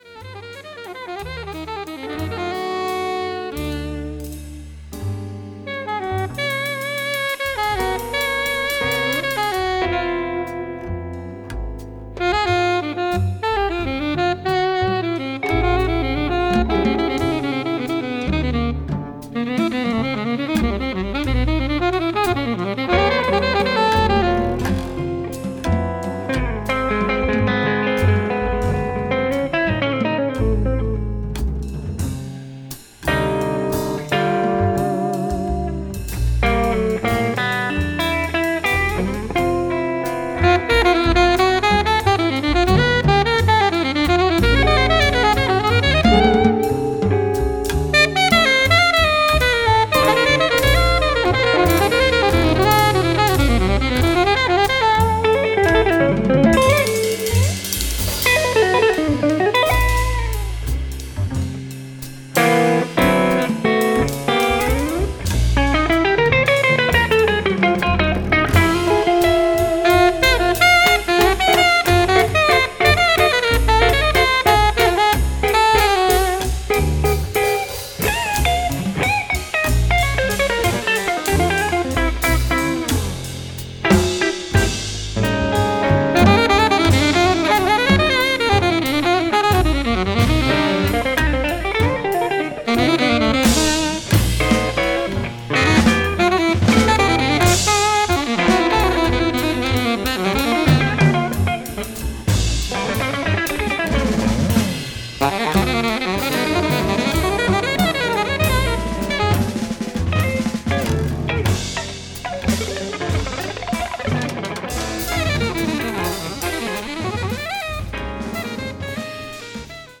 slow drawn out, bluesy masterpieces